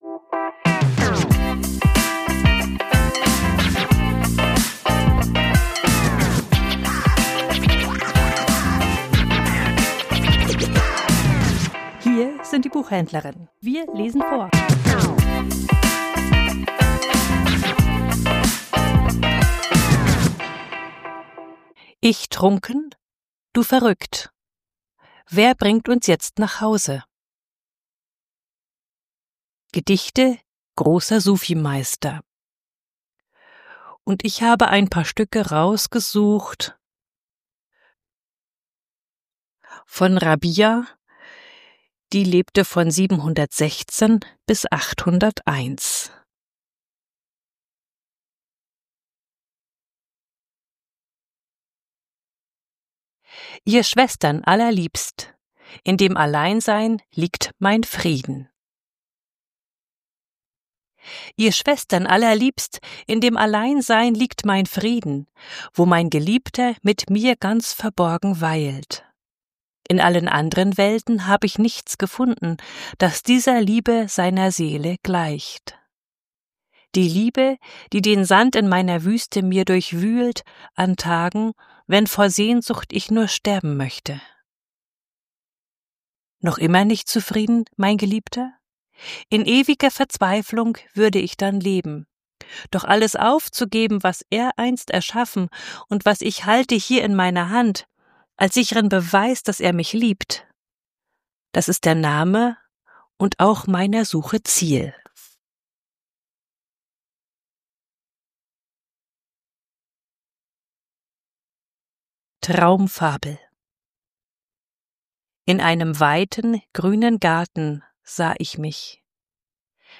Vorgelesen: Ich trunken, du verrückt. Wer bringt uns jetzt nach Haus?